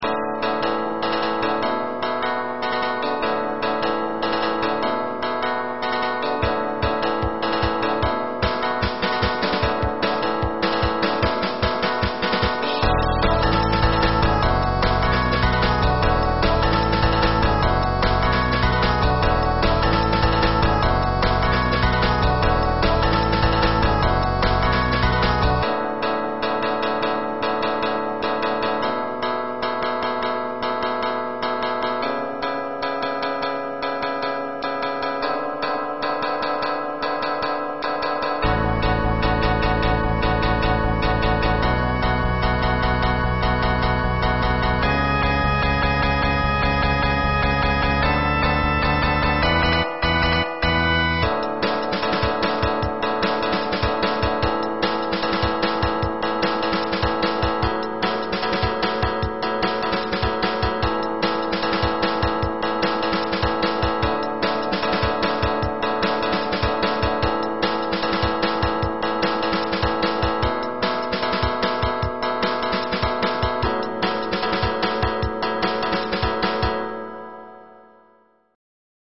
今回、譜面に起した曲はドラムのフィル等、かなり簡略化してやってしまいました。
で、今回作った曲は↓（まだ色々穴だらけだけど…これからドラムトラックとか、ベース・リードギターとか入れてきます。とりあえず、骨格だけ）